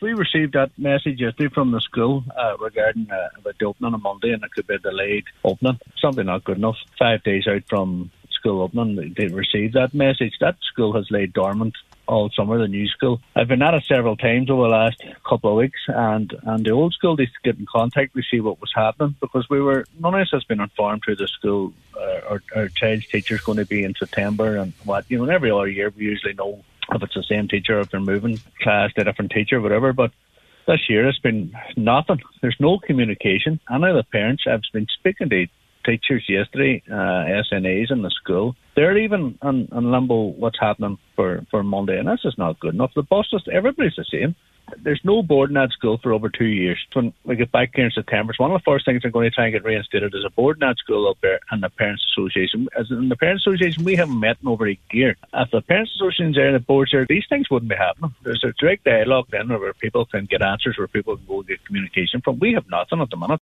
In April, the government said a resolution to capacity issues had been identified, but local Cllr Thomas Sean Devine says not much has happened since then.